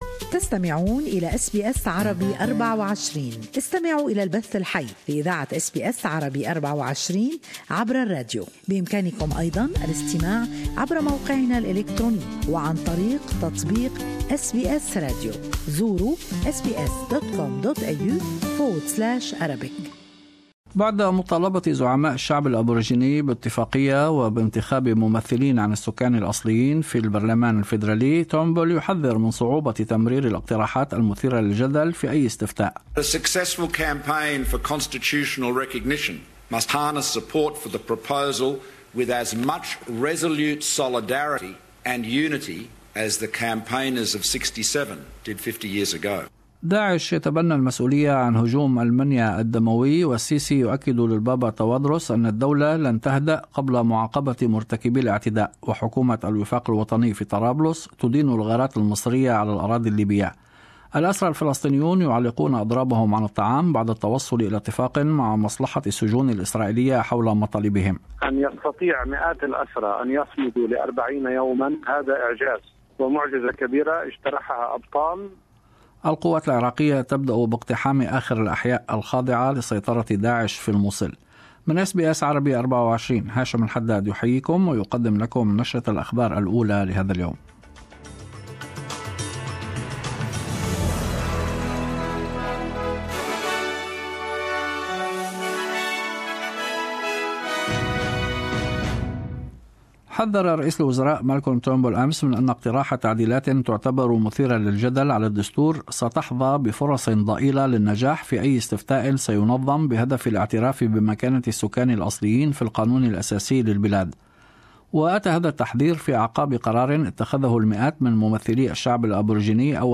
Latest Australian and world news in the morning news bulletin.